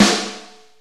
SUMMER SNR.wav